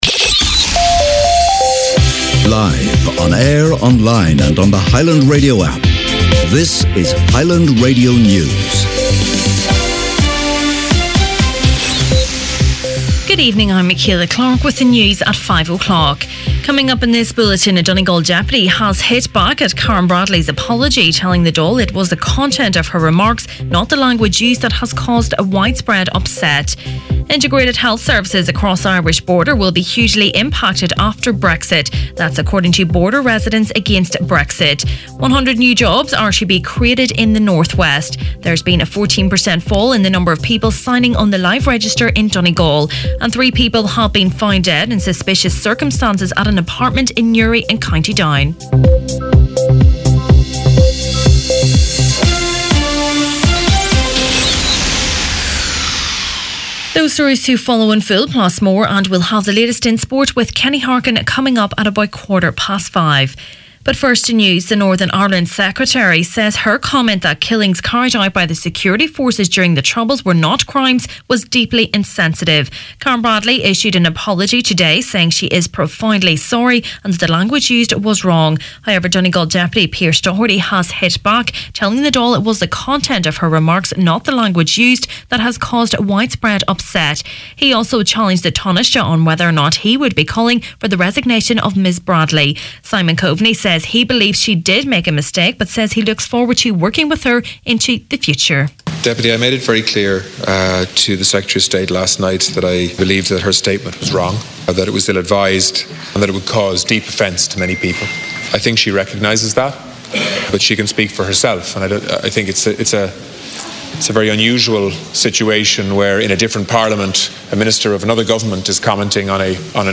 Main Evening News, Sport and Obituaries Thursday March 7th